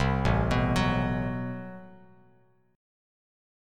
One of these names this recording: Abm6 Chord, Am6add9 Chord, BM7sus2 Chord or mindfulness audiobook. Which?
Am6add9 Chord